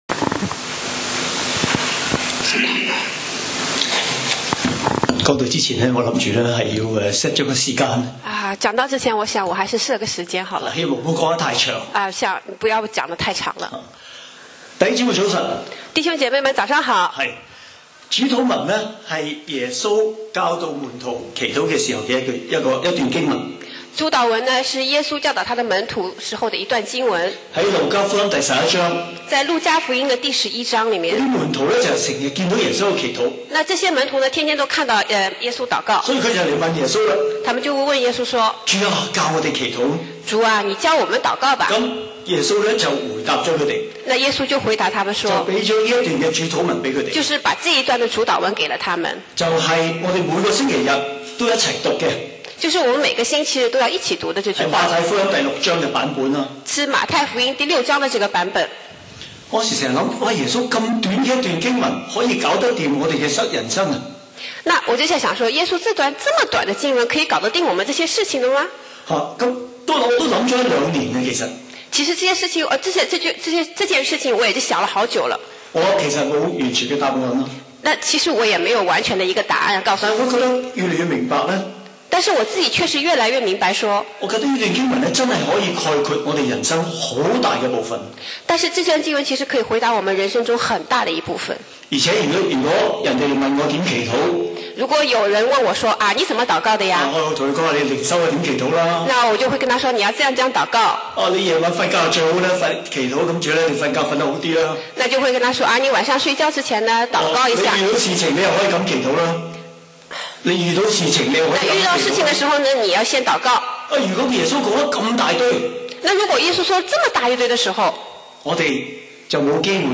講道 Sermon 題目 Topic：我們在天上的父 經文 Verses：馬太福音 Matthew 6:9 「。。我們在天上的父。。」